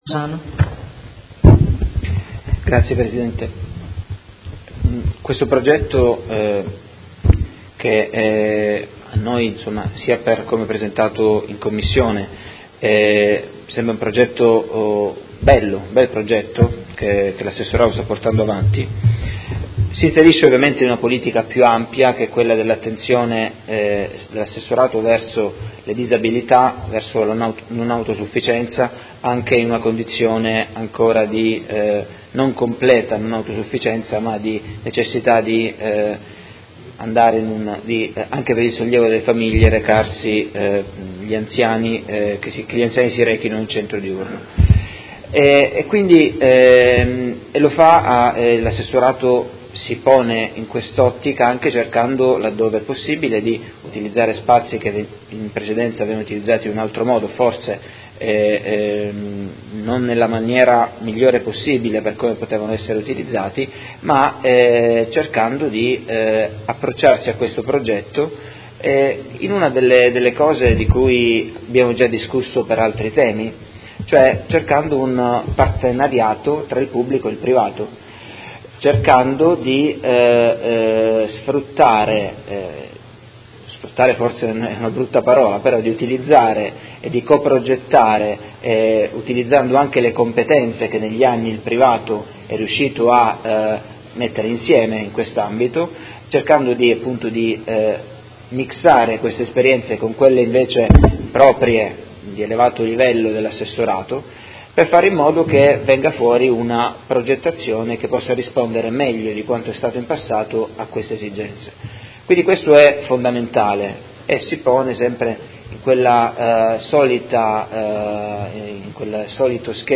Seduta del 25/05/2017 Dichiarazione di voto. Linee Guida per la pubblicazione di un Avviso pubblico di manifestazione di interesse per la coprogettazione e realizzazione di uno Spazio anziani rivolto a persone con limitate autonomie psicofisiche